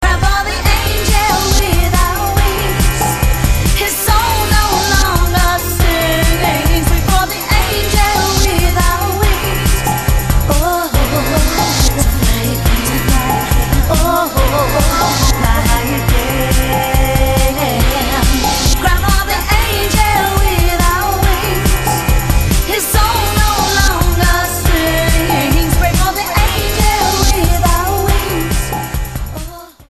STYLE: Pop
With a strong and passionate voice, rich and full